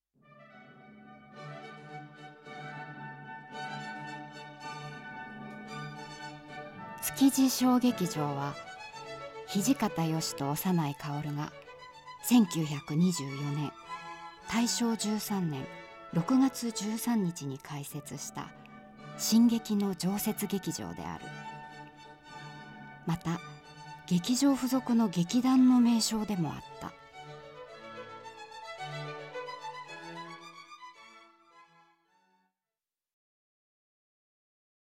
ナレーション
ボイスサンプル